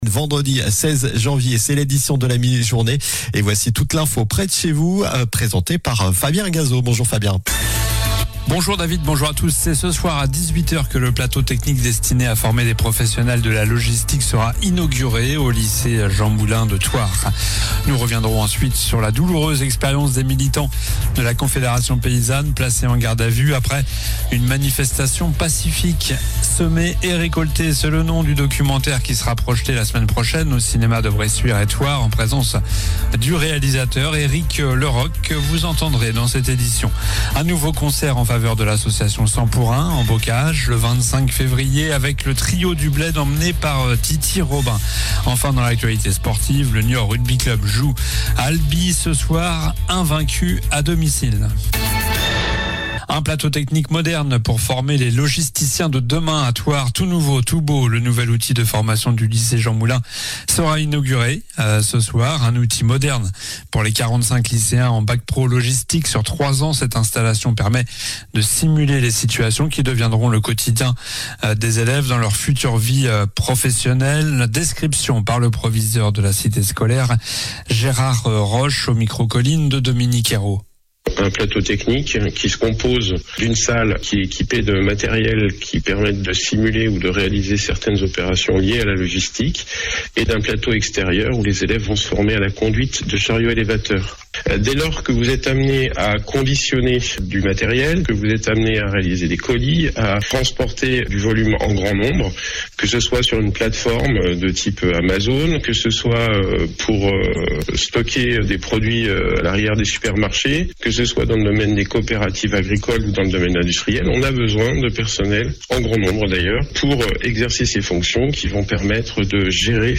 COLLINES LA RADIO : Réécoutez les flash infos et les différentes chroniques de votre radio⬦
L'info près de chez vous